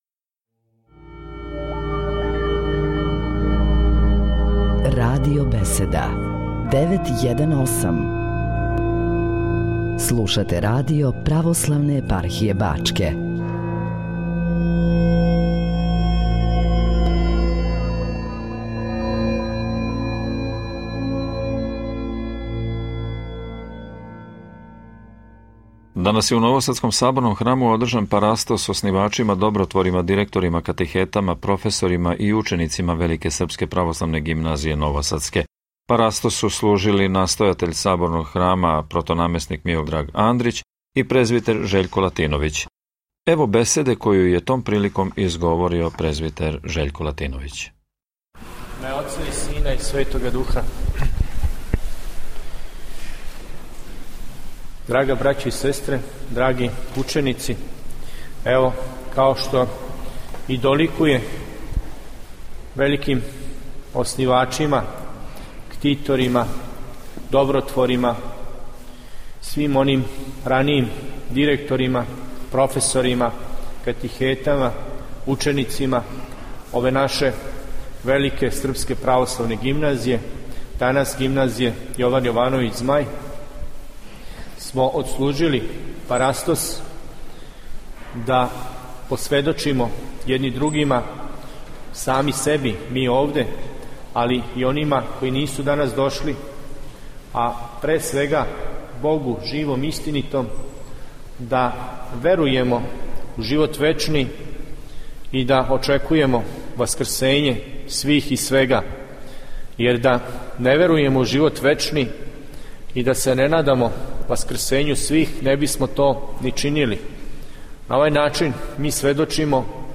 Парастос упокојеним оснивачима, управитељима и ђацима Велике српске православне гимназије Новосадске
Нови Сад